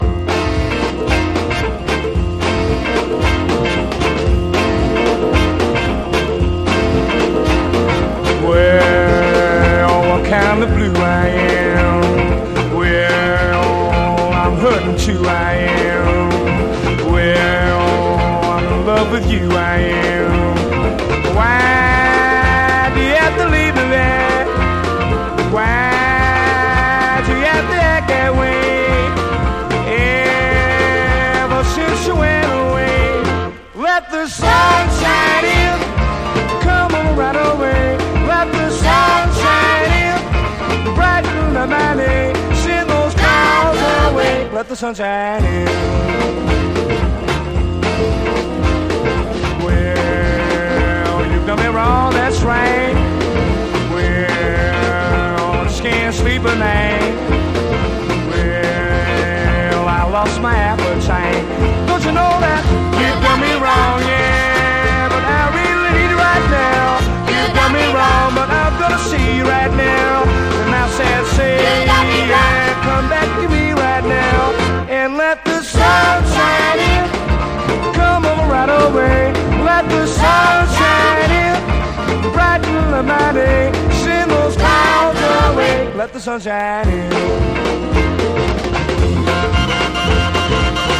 モッド・クラシックなキラー・カヴァー多数の1964年録音！